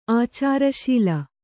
♪ ācāraśila